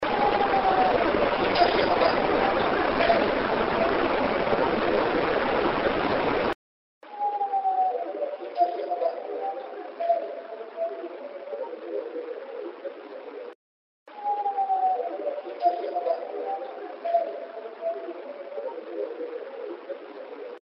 The last night we stayed at the Hotel Congress, I left the recorder on all night in Room 242 to see what I could find.
AUDIO RESULTS - Electronic Voice Phenomenon (EVP)
The ghost (or whatever is left) of the woman is crying out. The clip plays normal once, and with noise reduction twice.
PossibleVoice.mp3